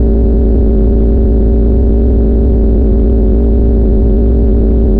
spaceEngineLow_004.ogg